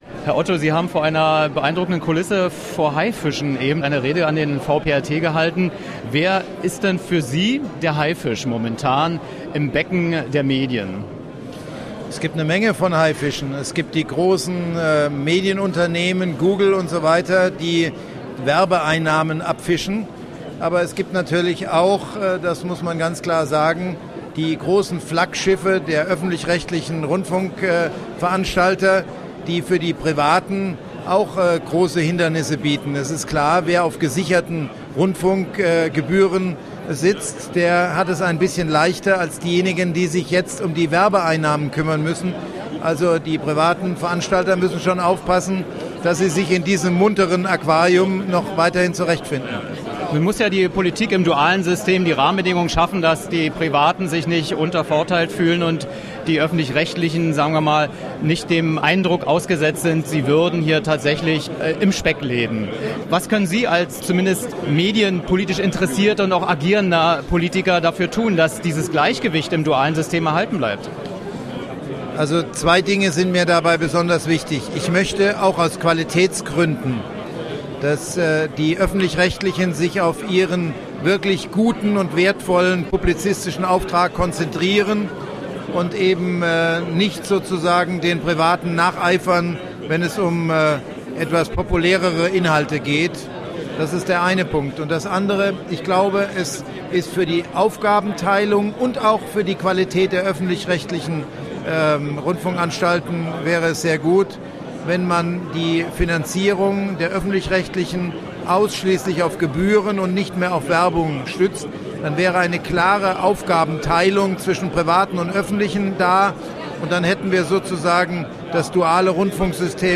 Was: Interview am Rande einer VPRT-Abendveranstaltung
Wo: Berlin, Zoo-Aquarium